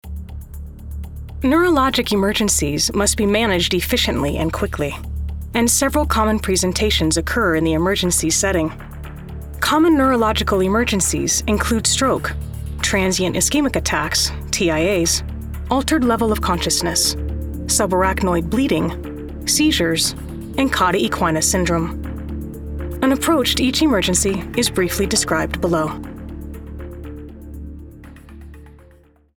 Female Voiceover
Medical Narration
Utilize a voice talent fluent in medical terminology and pronunciation to convey your tutorials, MOA’s, MOD’s, pharmaceutical ads, ISI’s, award ceremonies, aftercare videos, explainer videos for devices and procedures, etc. with precise and clear medical narration.